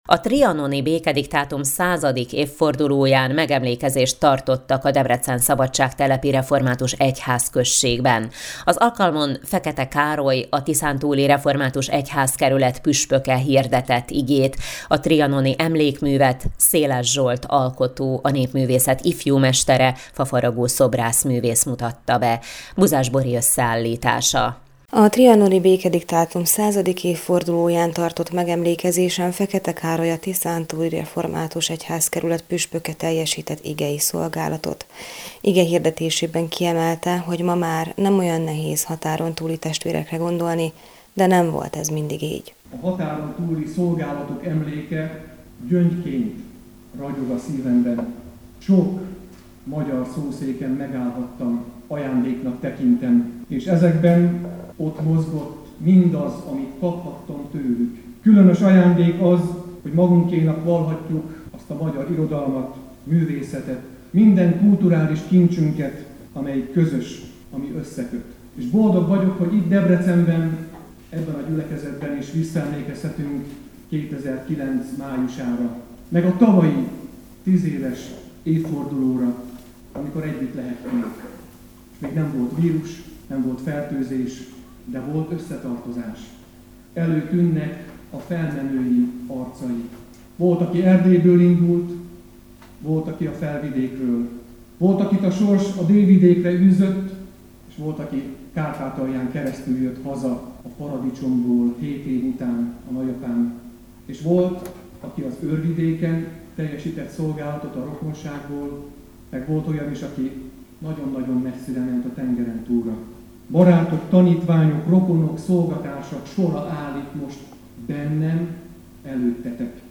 Trianoni megemlékezést tartottak a szabadságtelepi református templomban június 4-én. Az ünnepségen igét hirdetett Dr. Fekete Károly, a Tiszántúli Református Egyházkerület püspöke